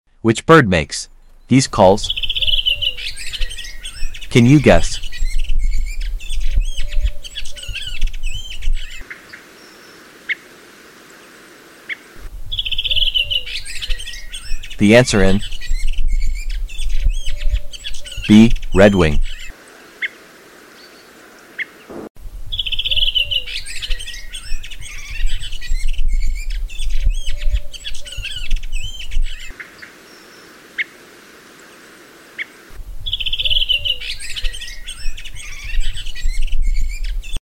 Which bird makes these calls..?..Can sound effects free download
bird calls bird sounds